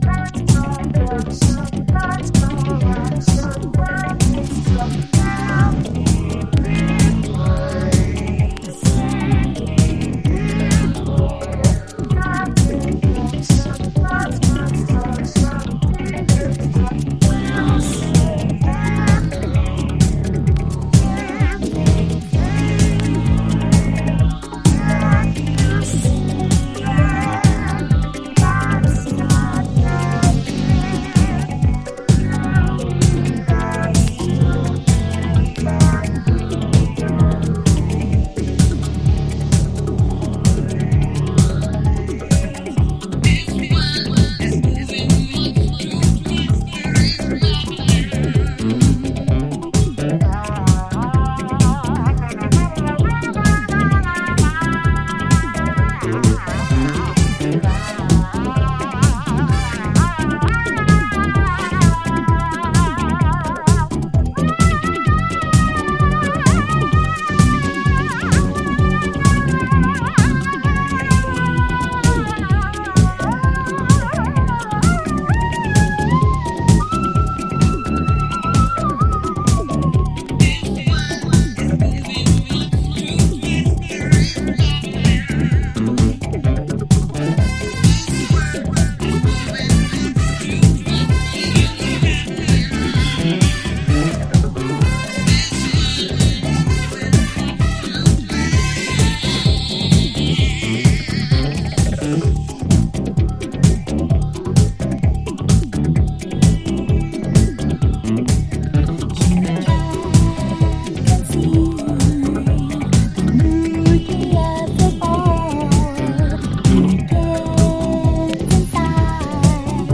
完全にハウス/ディスコ・ダブしている異端エレクトリック･ディスコ